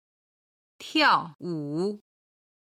今日の振り返り！中国語発声